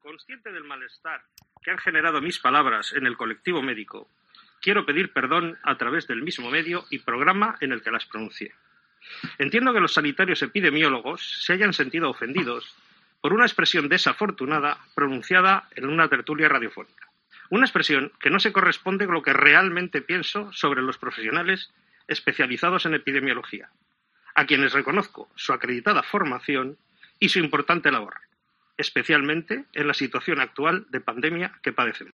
El juez Garrido pide perdón en la tertulia de Radio Popular
Ha dado lectura a un escrito en el que se ha disculpado al colectivo de epidemiólogos.